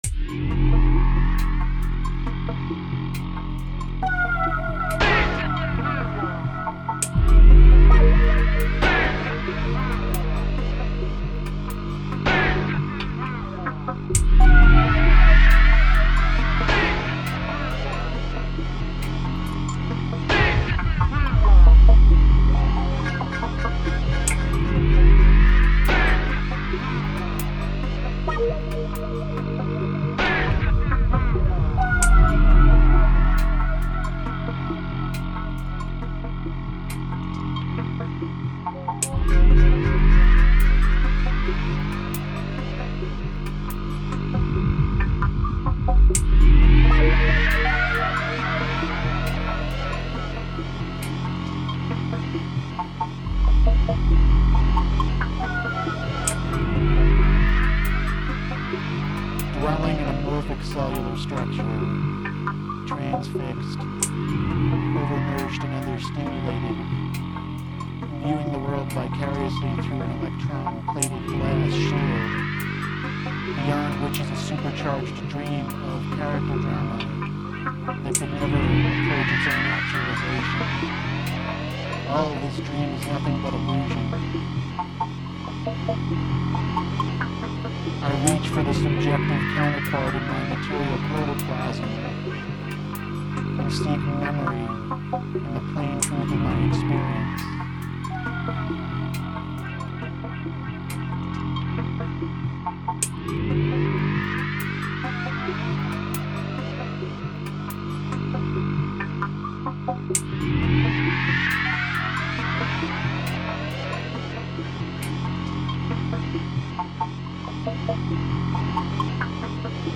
this is bith subtle and wise
House
Ambient
Trance